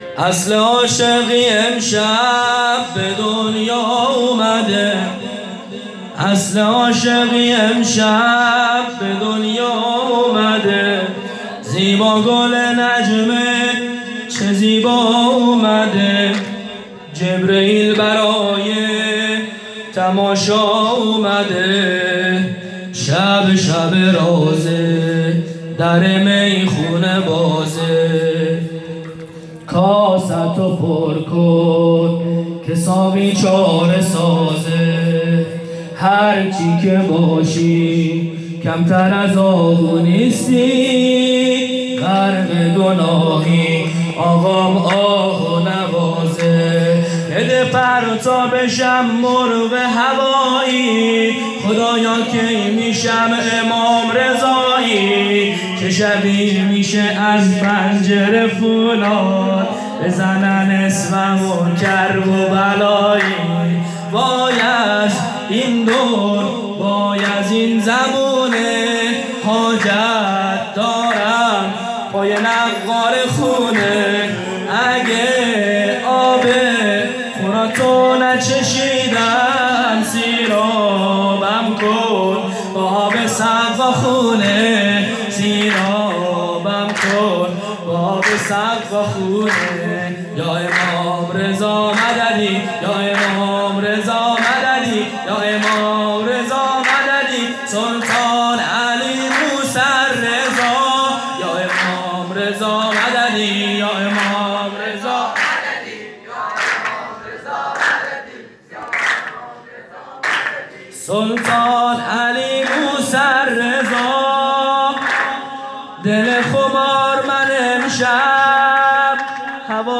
4-سرود-اصل-عاشقی-امشب-به-دنیا-اومده.mp3